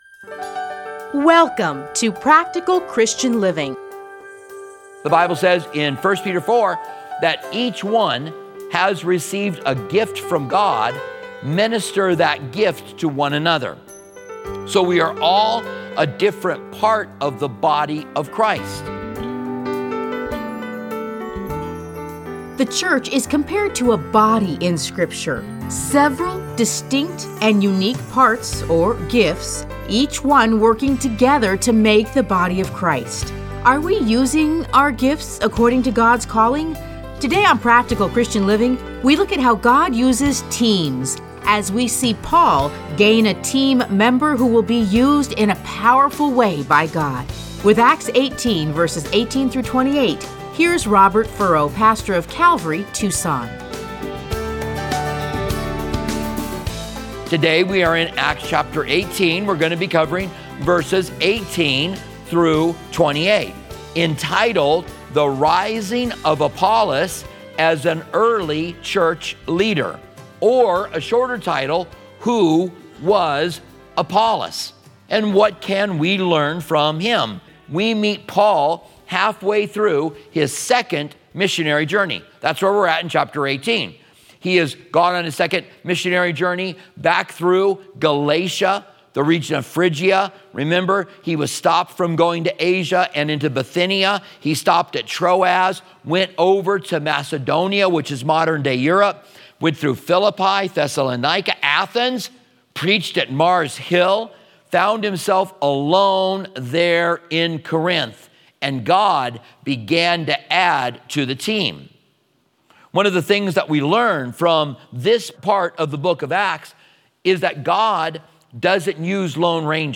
Listen to a teaching from Acts 18:18-28.